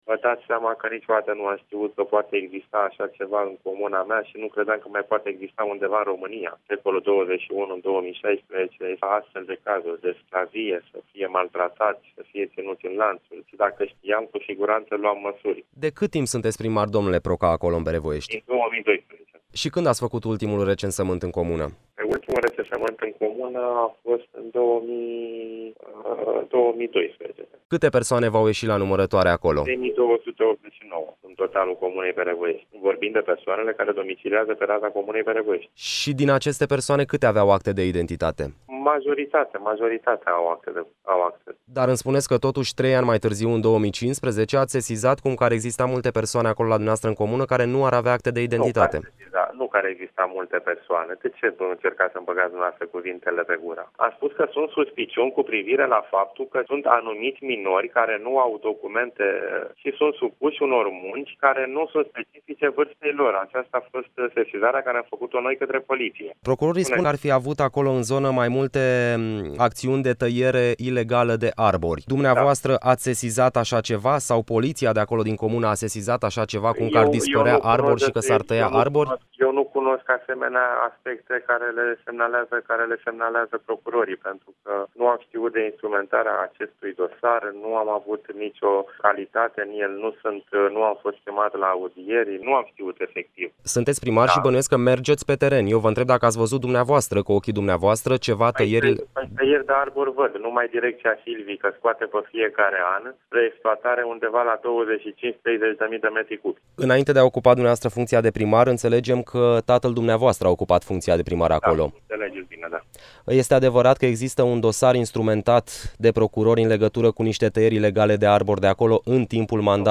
ASCULTĂ MAI JOS INTERVIUL INTEGRAL CU BOGDAN PROCA, PRIMARUL DIN BEREVOEȘTI